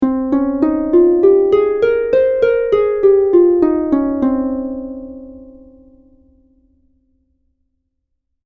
Escala frigia
arpa
sintetizador